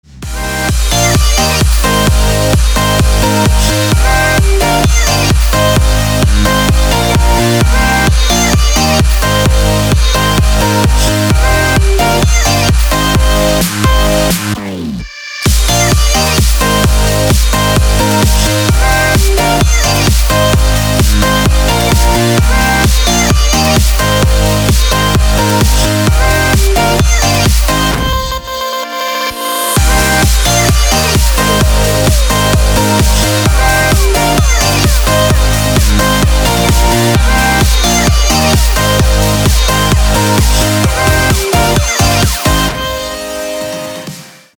• Качество: 320, Stereo
громкие
dance
Electronic
электронная музыка
club
энергичные
забавный голос
progressive trance
Trance
бодрые